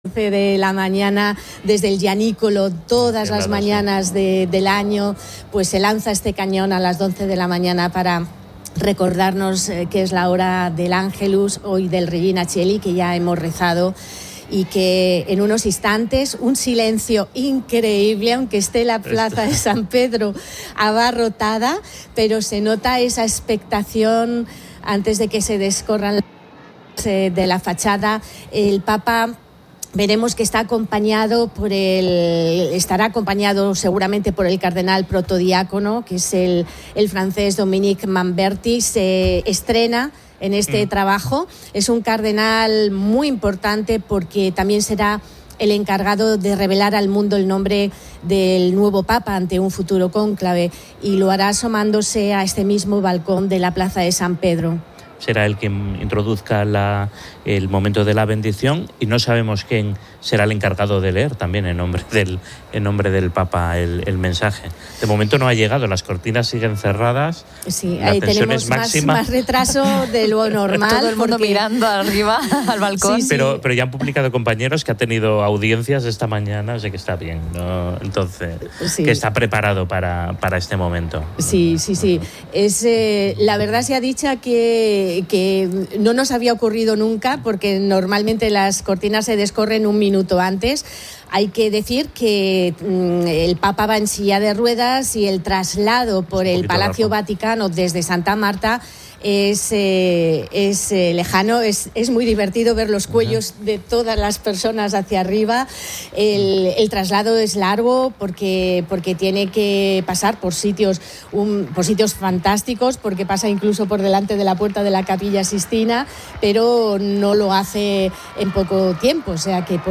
Hora completa del programa Fin de Semana de 12:00 a 13:59 ...